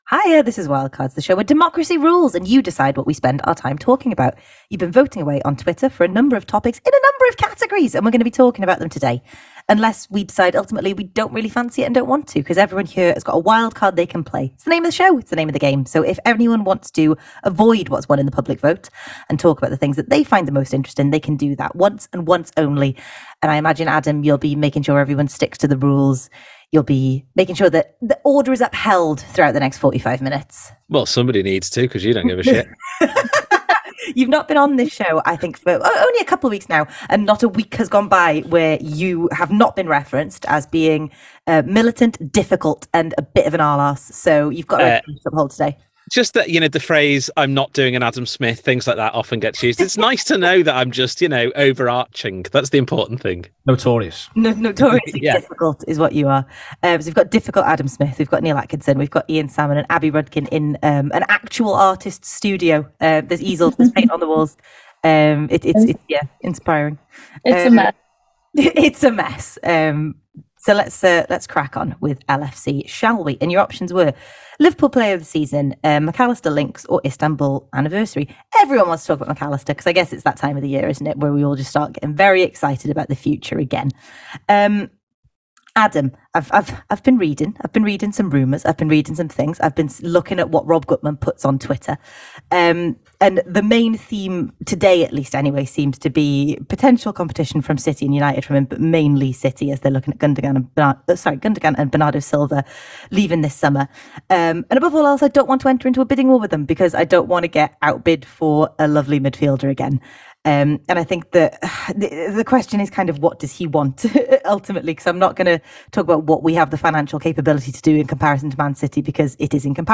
The Anfield Wrap’s Wildcards panel discuss Liverpool Alexis Mac Allister links, Newcastle United in the Champions League and more.